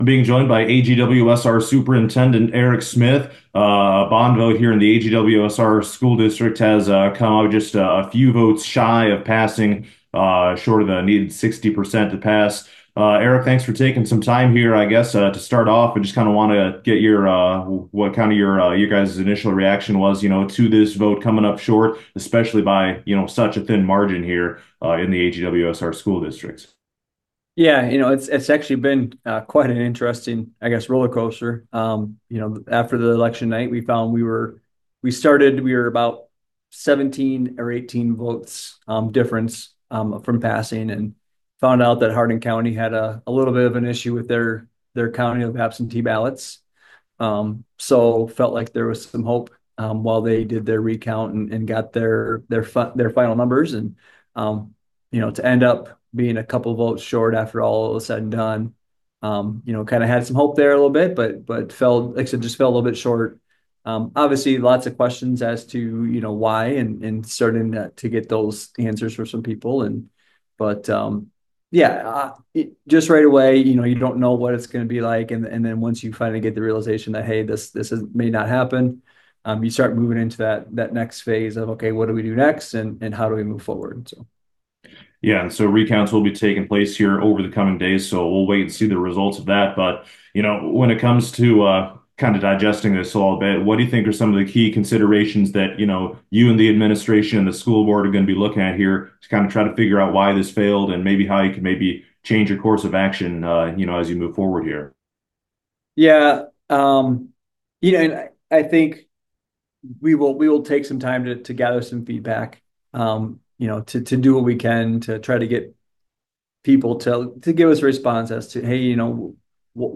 interview: